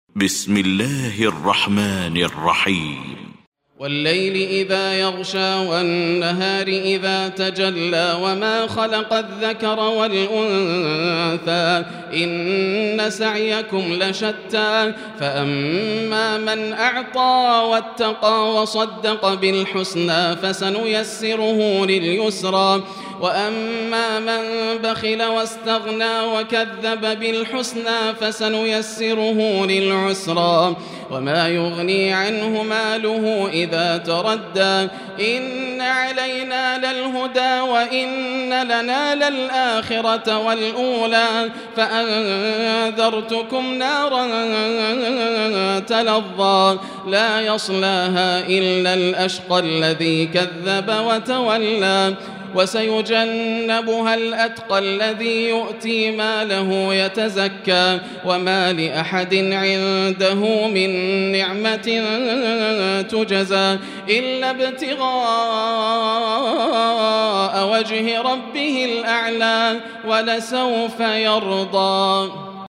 المكان: المسجد الحرام الشيخ: فضيلة الشيخ ياسر الدوسري فضيلة الشيخ ياسر الدوسري الليل